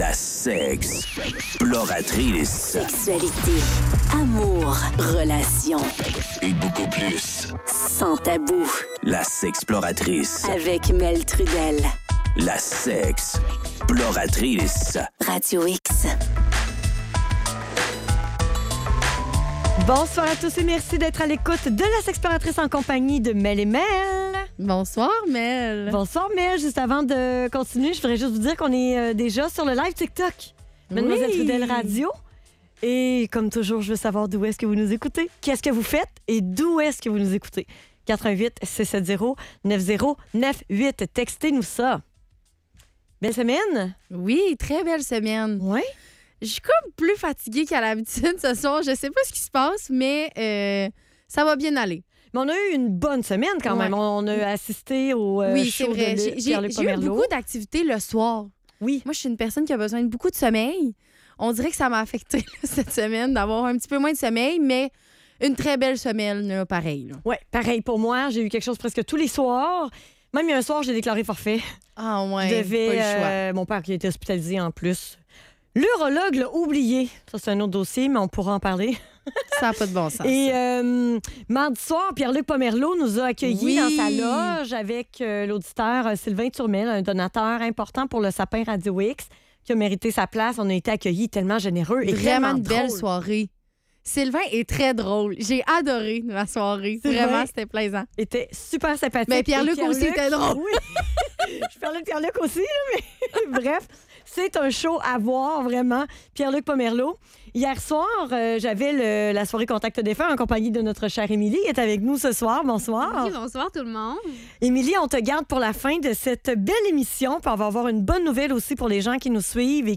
Médium et tarologue en studio